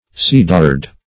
\ce"dared\ (s[=e]"d[~e]rd)
cedared.mp3